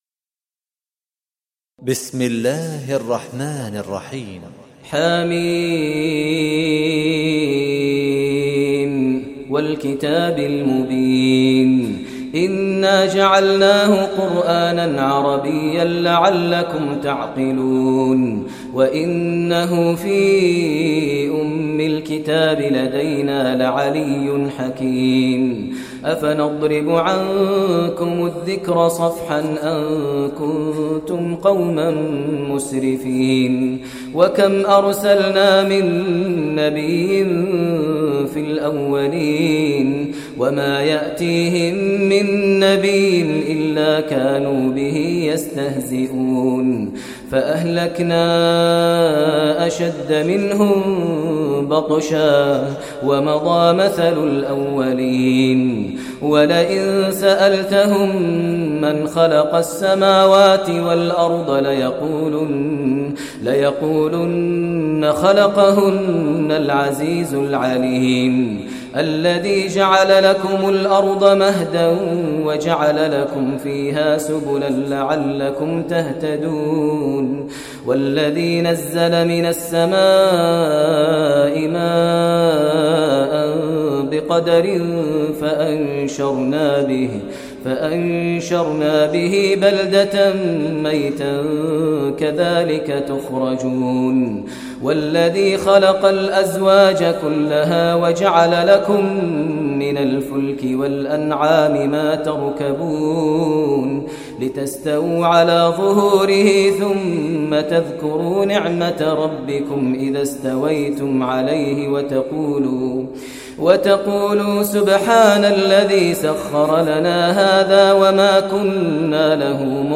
Listen online and download Beautiful Tilawat / Recitation of Surah Az Zukhruf in the voice of Sheikh Maher al Mueaqly.